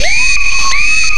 SCREAMFX  -R.wav